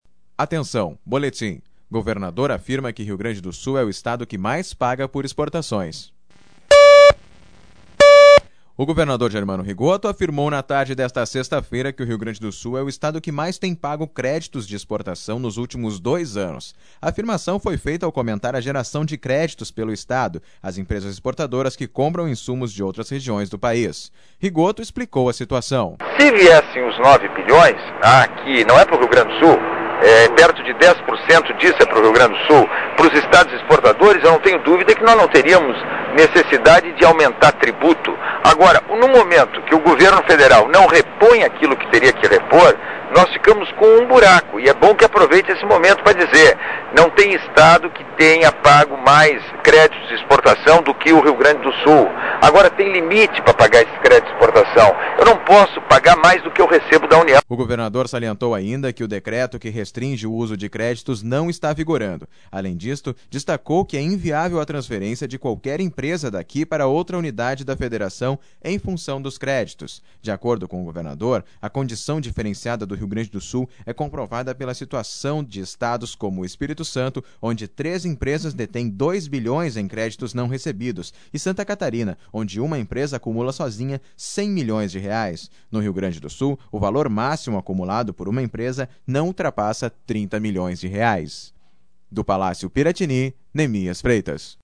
2005-02-04-governador-credito-exportacao.mp3